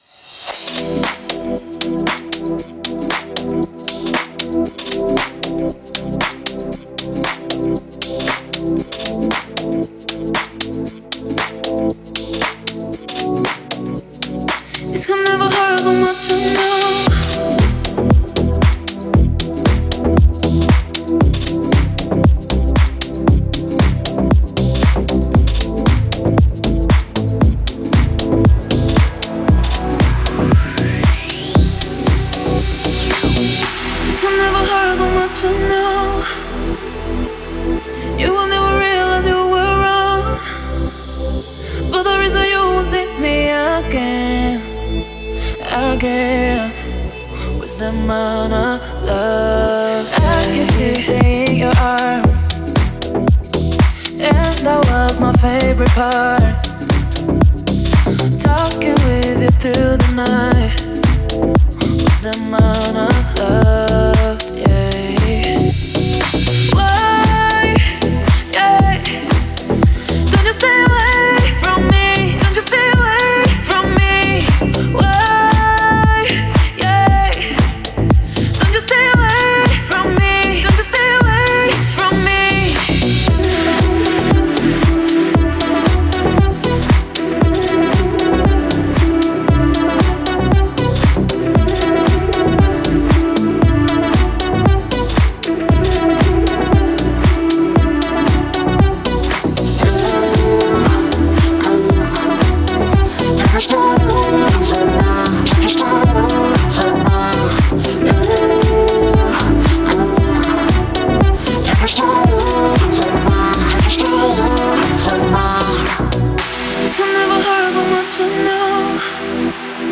это зажигательная трек в жанре EDM